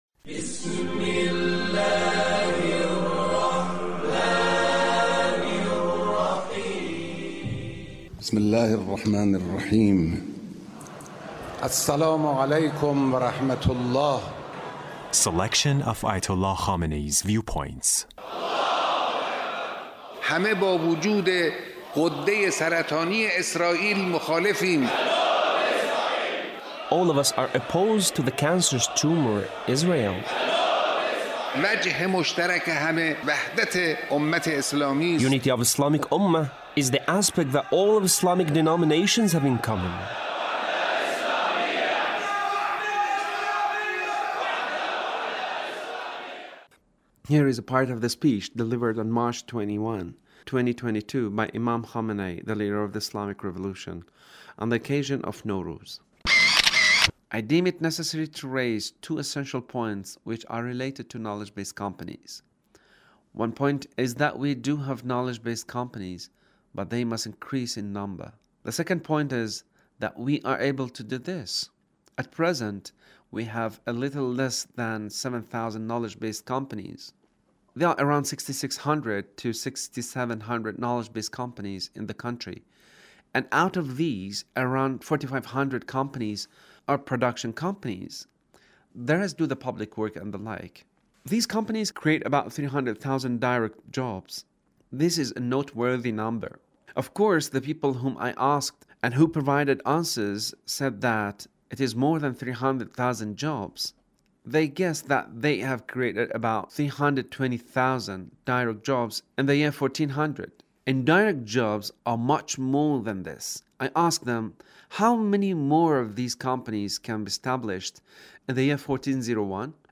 Leader's Speech (1666)